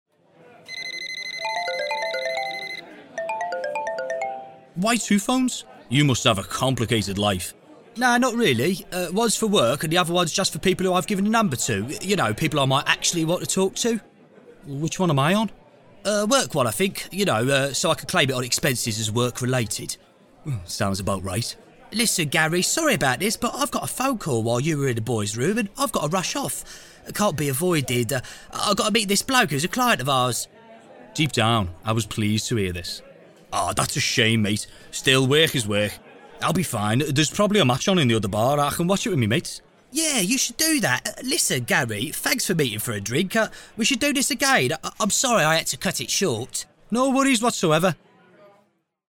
20s-30s. Male. Liverpool. Studio.